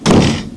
slam.aif